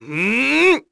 Neraxis-Vox_Casting2_kr.wav